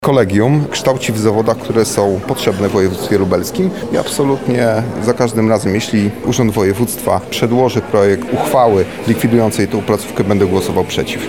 Paweł Kurek – mówi Paweł Kurek, radny Województwa Lubelskiego.